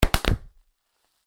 Big BubbleWrap